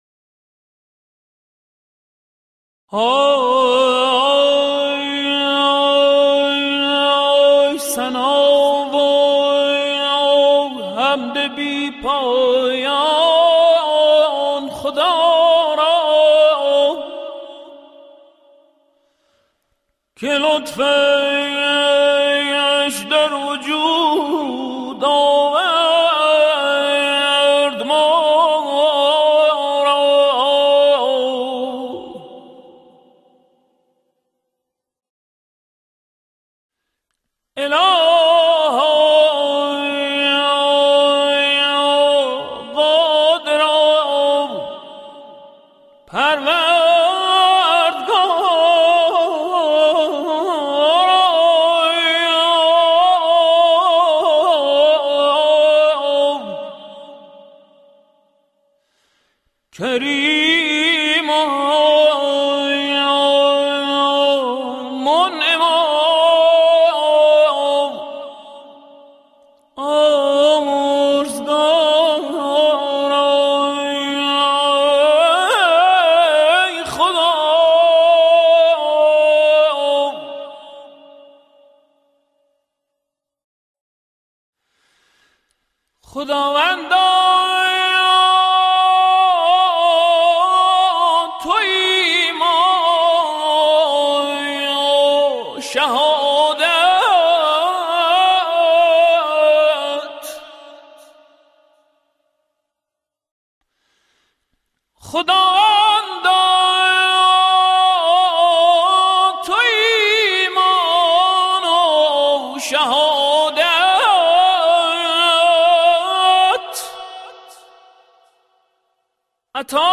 «مناجات خوانی»
این آواز در دستگاه شور و بر اساس اشعار سعدی و باباطاهر خوانده شده است.